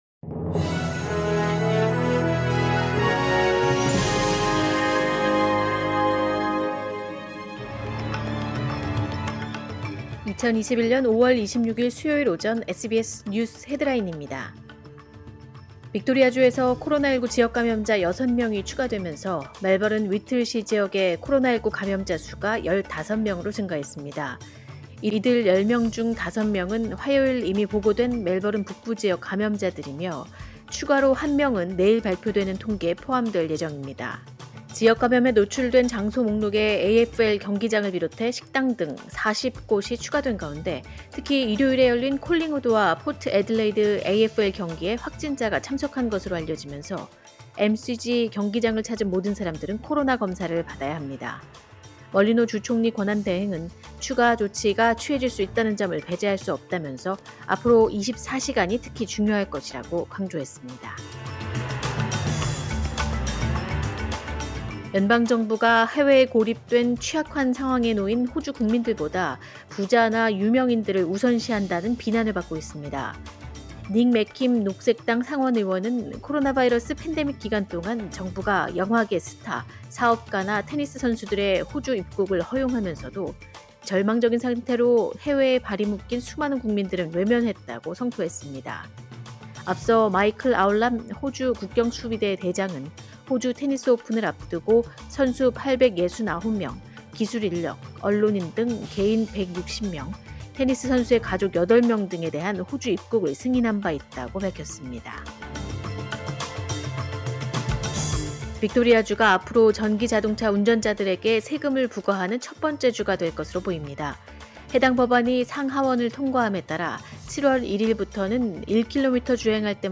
2021년 5월 26일 수요일 오전의 SBS 뉴스 헤드라인입니다.